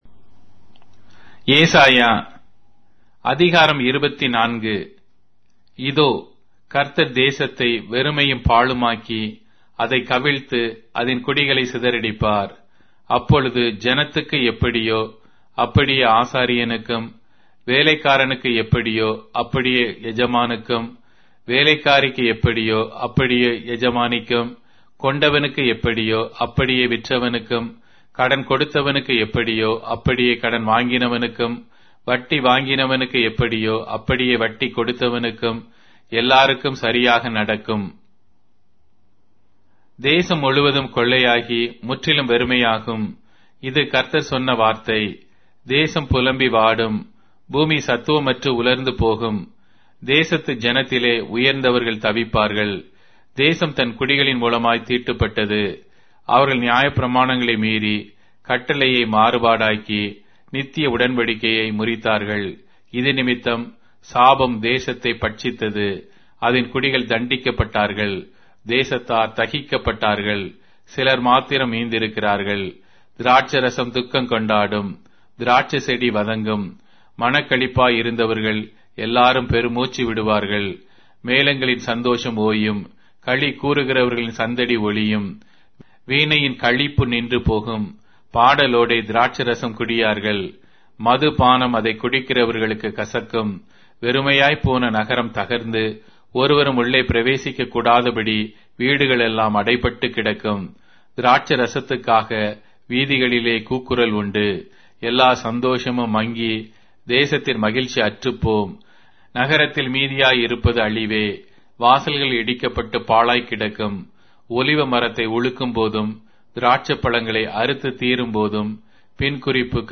Tamil Audio Bible - Isaiah 47 in Tev bible version